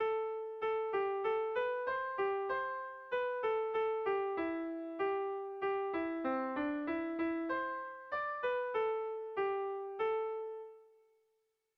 Erromantzea
Eibar < Debabarrena < Gipuzkoa < Euskal Herria
AB